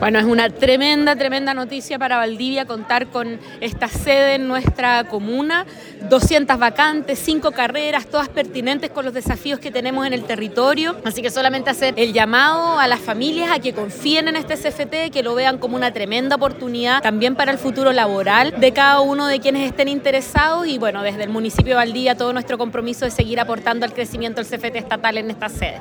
ALCALDESA-CARLA-AMTMANN.mp3